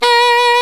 Index of /90_sSampleCDs/Roland L-CD702/VOL-2/SAX_Alto Short/SAX_Pop Alto
SAX A 3 S.wav